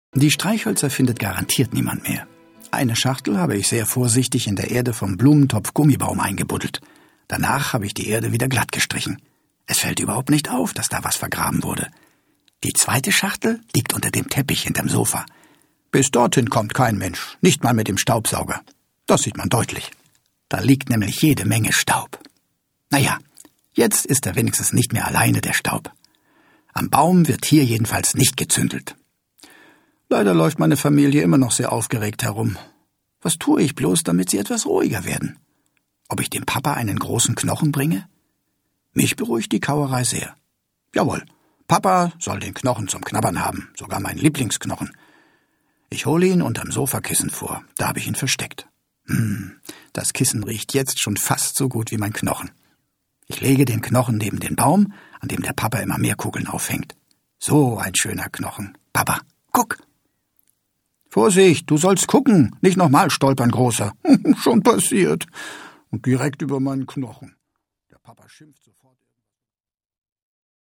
Peer Augustinski (Sprecher)